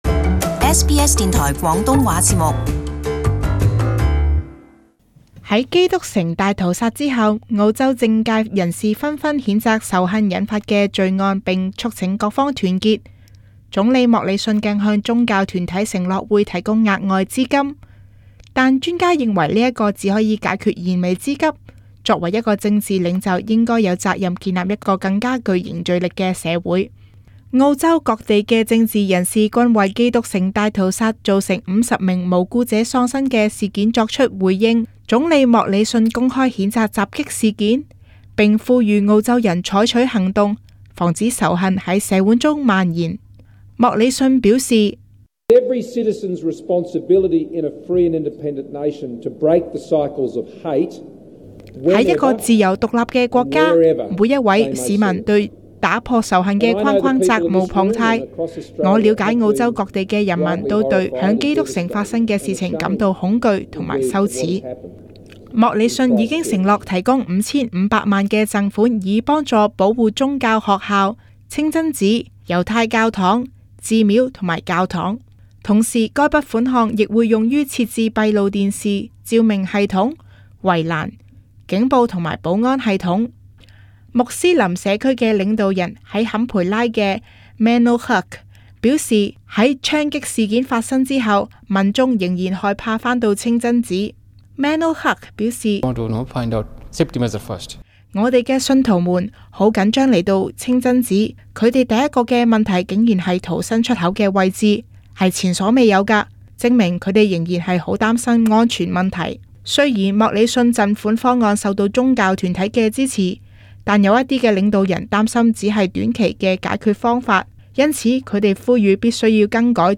【時事報導】莫理遜危機應變被批評